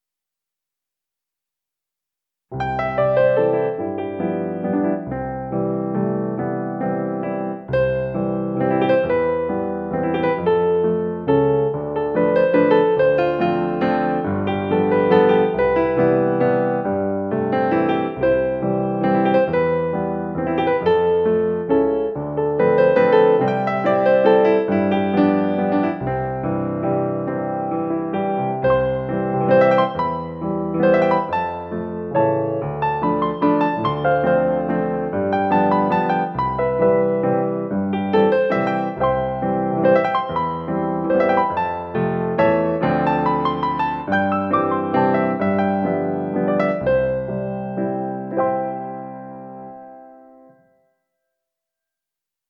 Piano accompaniment
Genre Italian songs
Musical Period Romantic
Tempo 45
Rhythm Moderato Waltz
Meter 3/4